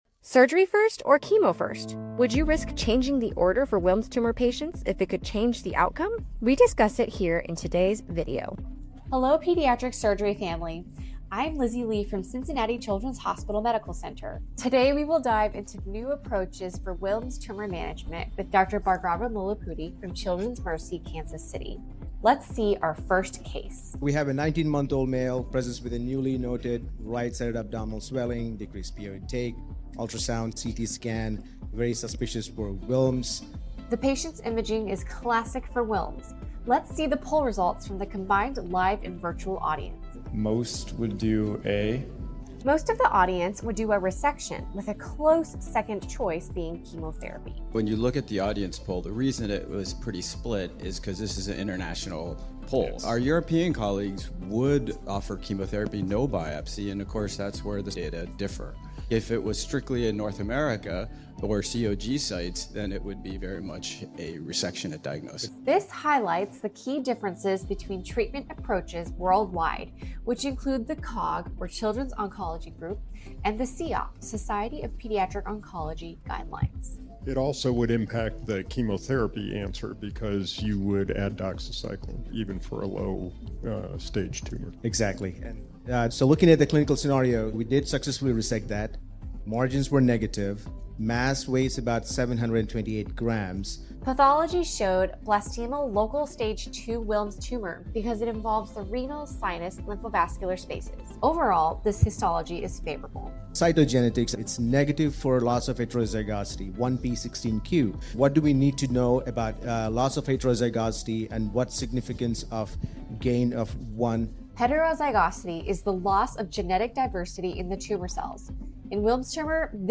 In this session from the 12th Annual Update Course in Pediatric Surgery